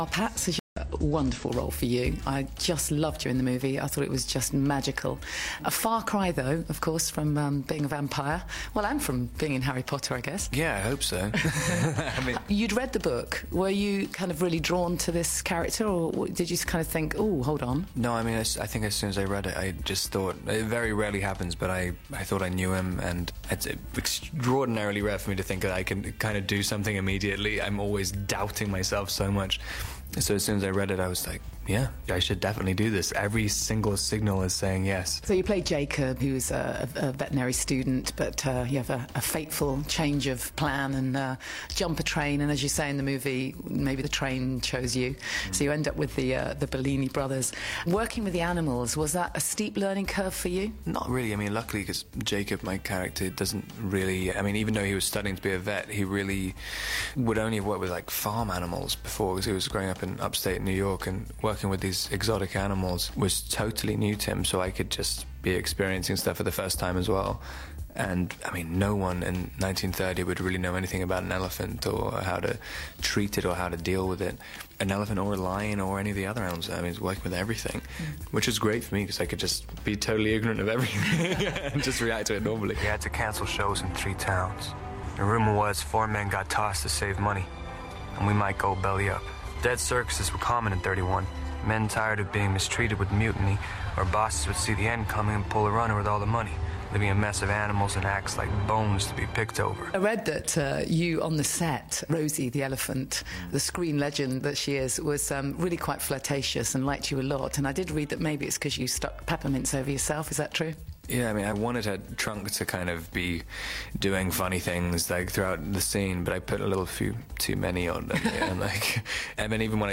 Steve Wright in The Afternoon on BBC2 has Robert Pattinson & Reese Witherspoon visiting today.